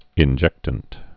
(ĭn-jĕktənt)